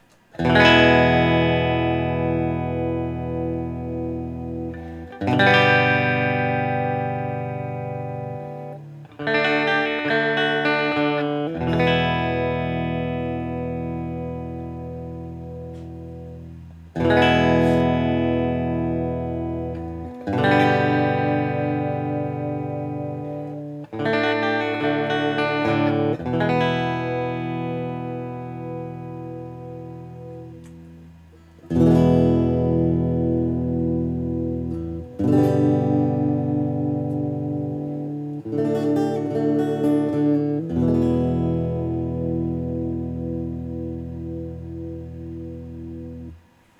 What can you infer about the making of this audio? For these recordings I used my normal Axe-FX Ultra setup through the QSC K12 speaker recorded into my trusty Olympus LS-10. For this guitar I recorded each selection with the tone set to 10, then 7, then 0.